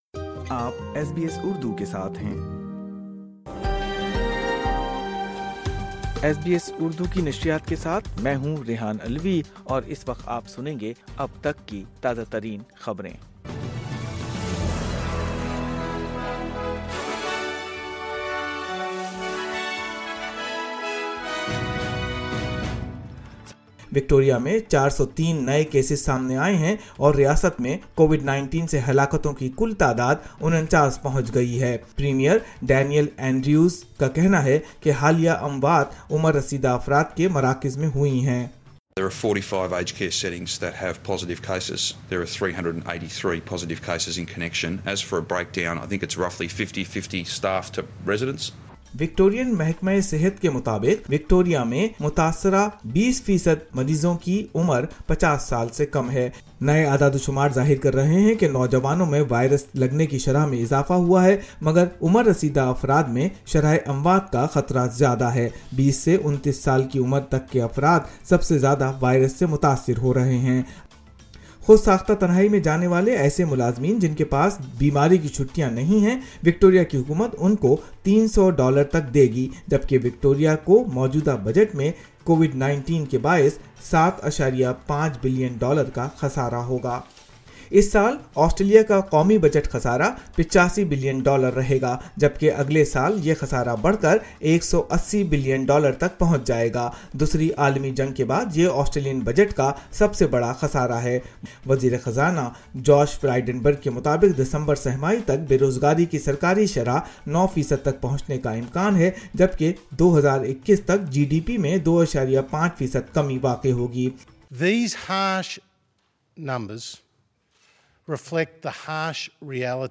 اردو خبریں 23 جولائی 2020